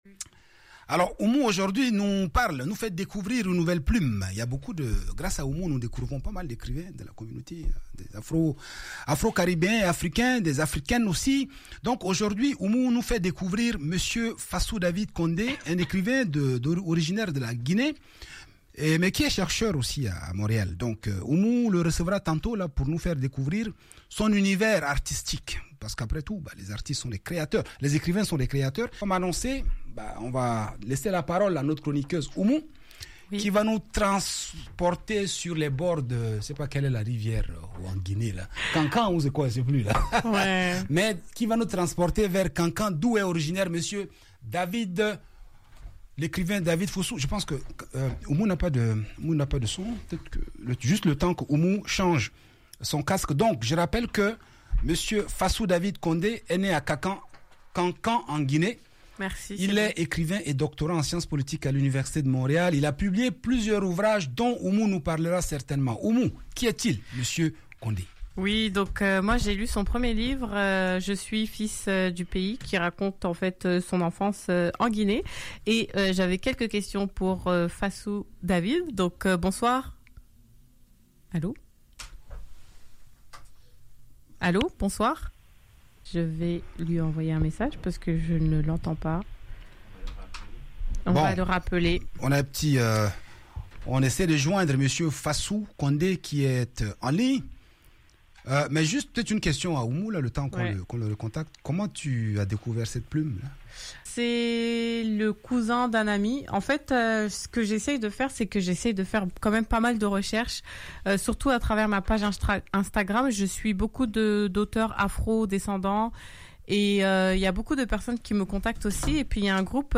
Voici mon entrevue avec lui: